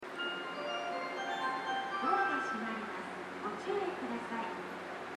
スピーカーはＴＯＡ型が設置されており音質も高音質です。スピーカーの高さも低めですが 音量がやや小さめです。
発車メロディーフルコーラスです。